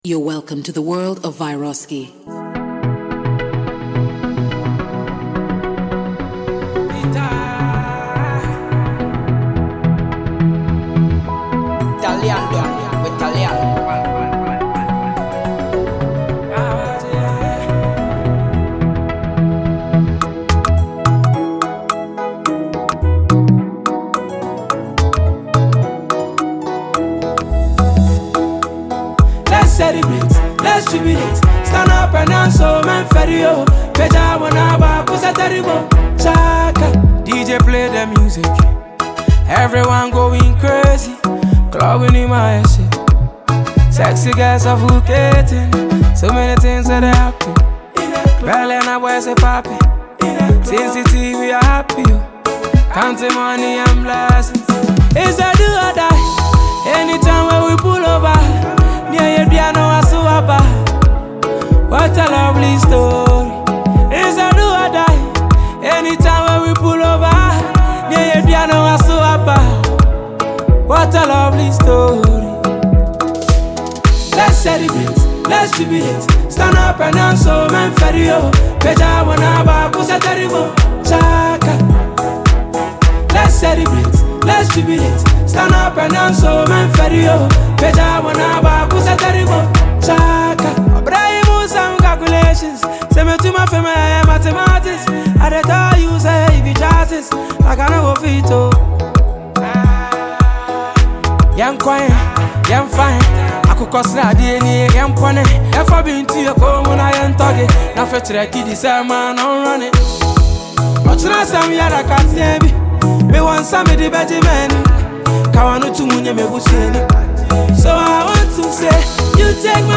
soulful melodies